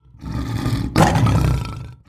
roar.mp3